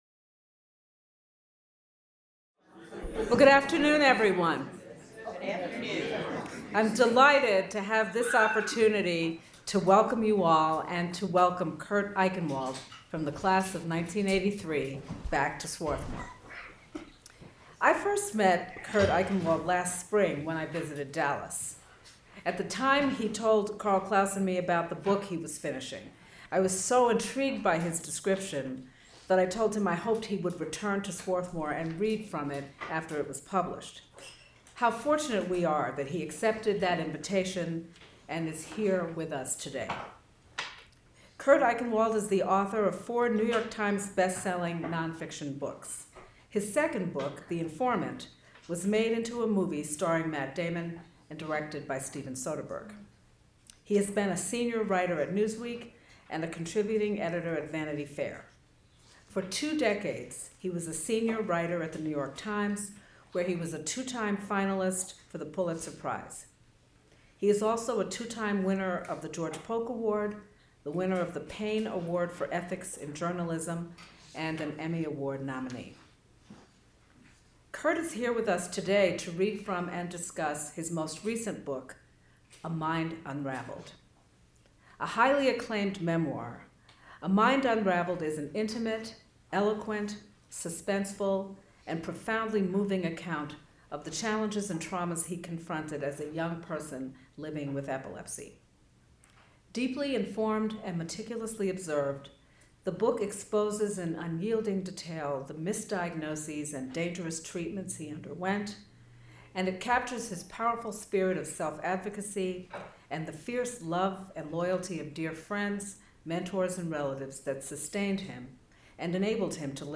Author Kurt Eichenwald ’83 Discusses A Mind Unraveled, Recounts His Life with Epilepsy
Kurt Eichenwald '83 without Q & A.mp3